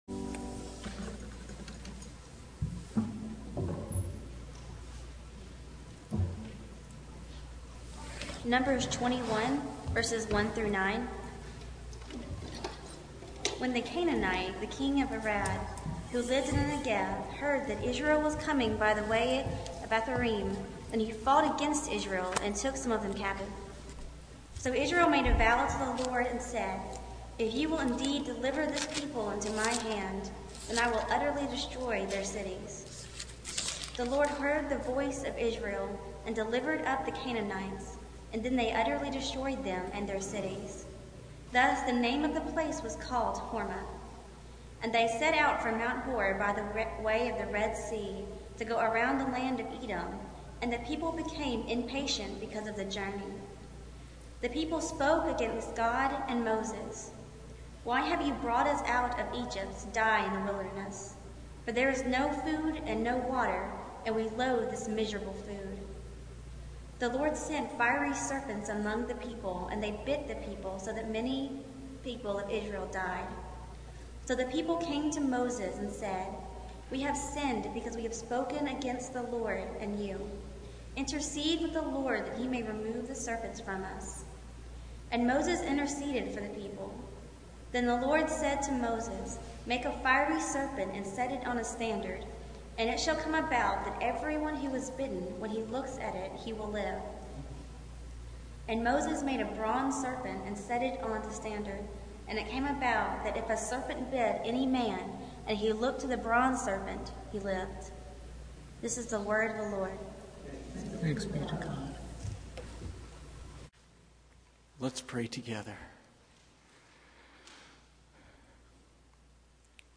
Passage: Numbers 21:1-9 Service Type: Sunday Morning